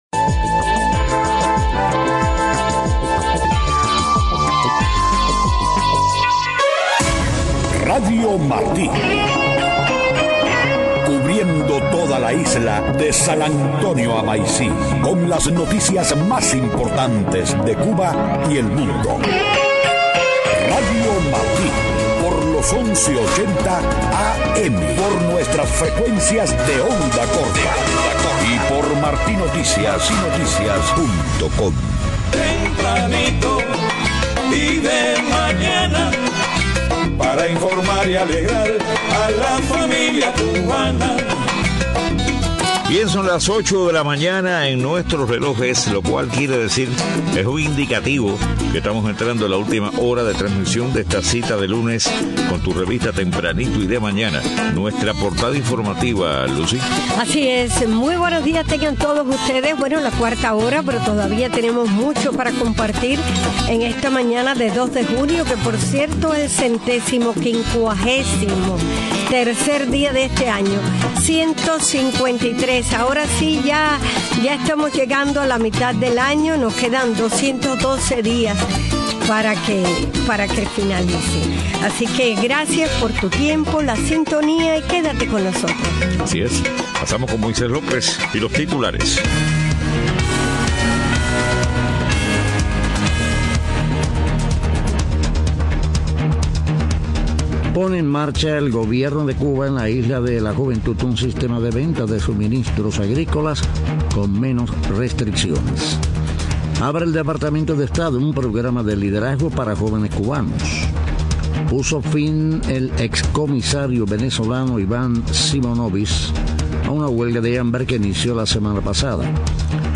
8:00 a.m. Noticias: En Isla de la Juventud comenzó nuevo sistema de comercialización de suministros agrícolas con menos restricciones del Gobierno.